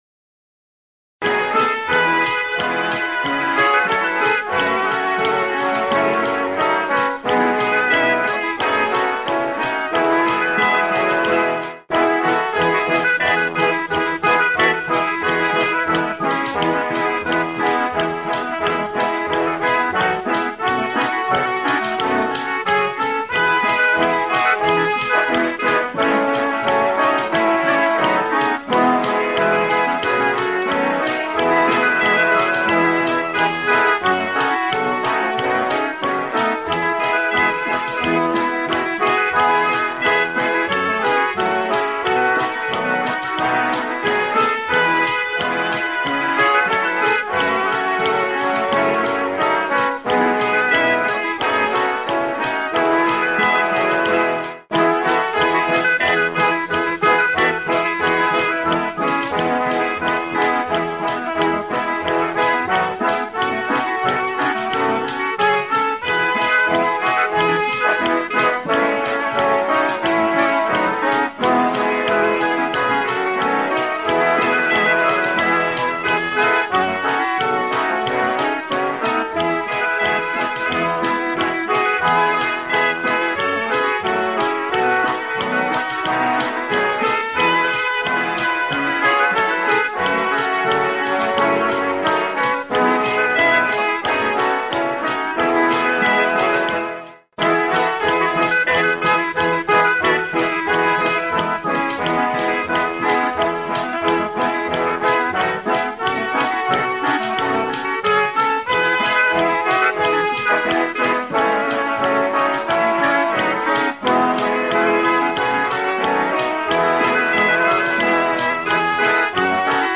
Click to play PoerukaayathamAagivitoem_2  [This accompaniment includes a regular prelude]